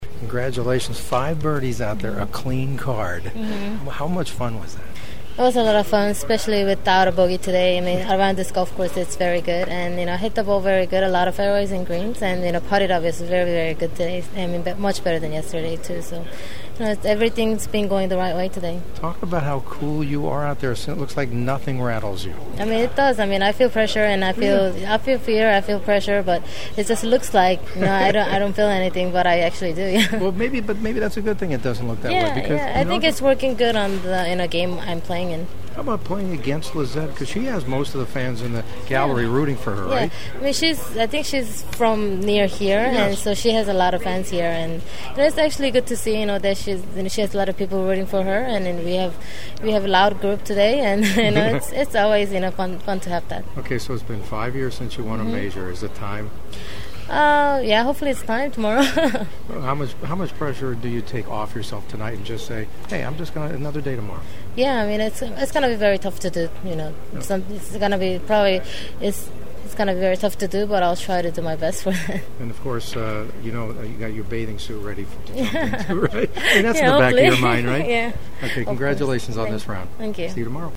Here’s my post-round chat with Inbee who was all smiles and ready for the big challenge: